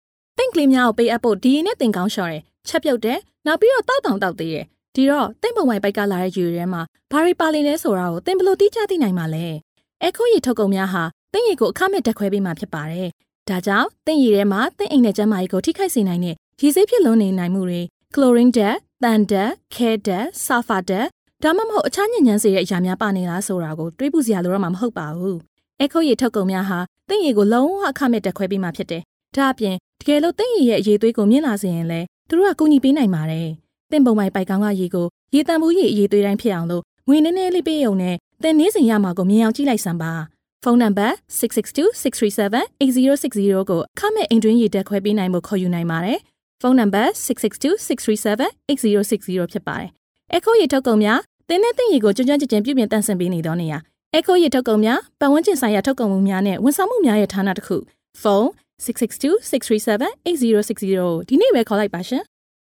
Voiceover Artists
EQHO provides multi-language solutions from its in-house recording facilities
Burmese Female 03295
NARRATION
COMMERCIAL